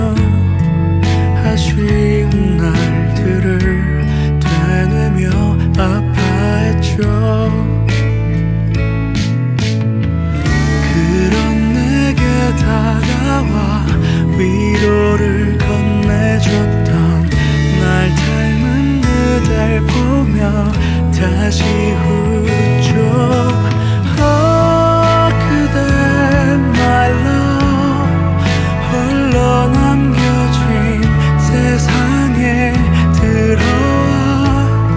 Жанр: Музыка из фильмов / Саундтреки
# TV Soundtrack